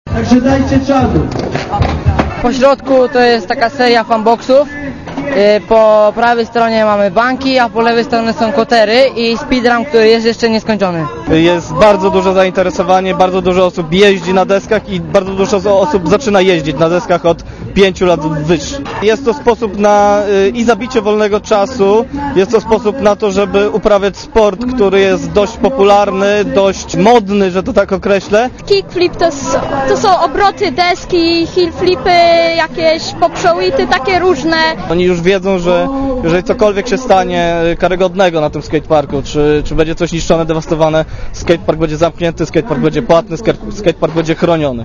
Mówia młodzi Opolanie (174 KB)